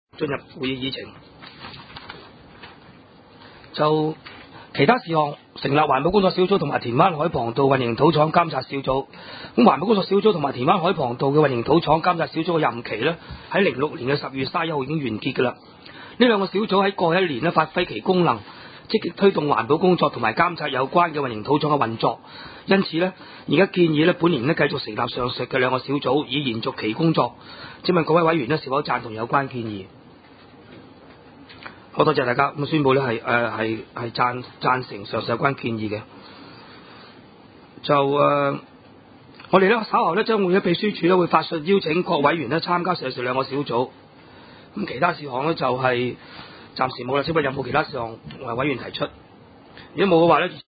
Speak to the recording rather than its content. Venue: SDC Conference Room